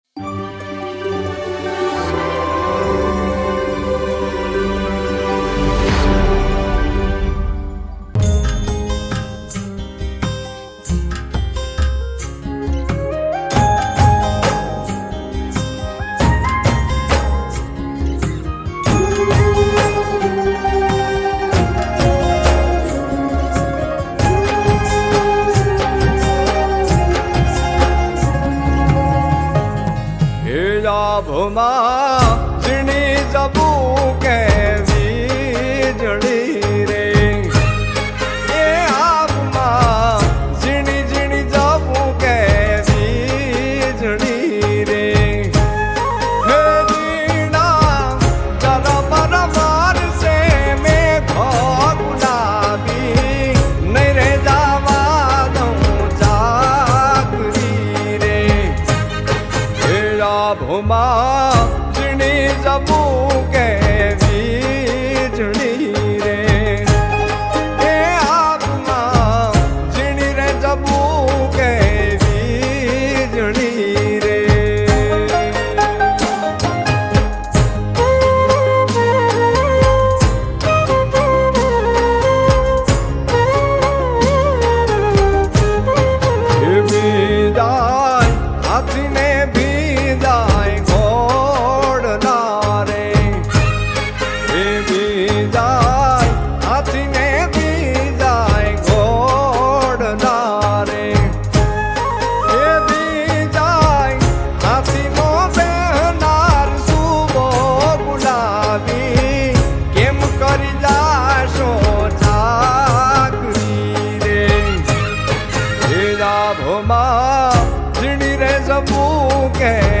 ગીત સંગીત ગરબા - Garba
New Lok Geet